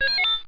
sound_cerror.mp3